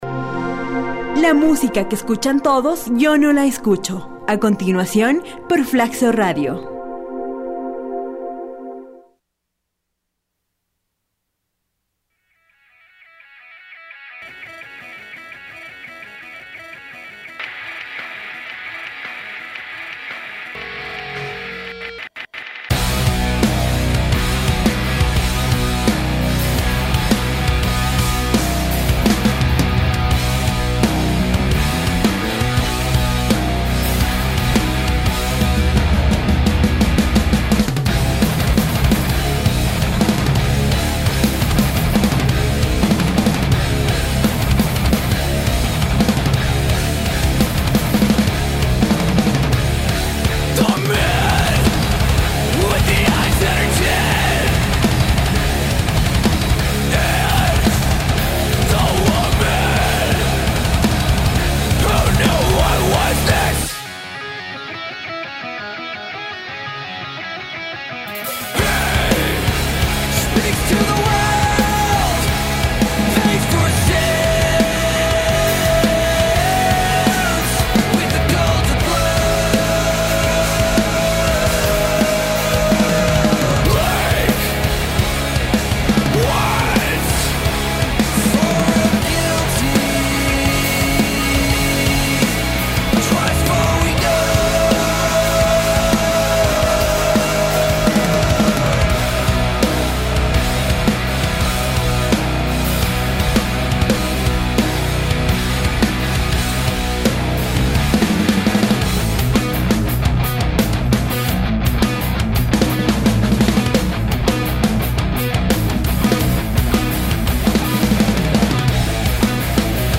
stoner rock
un tema de rock con un estilo muy clásico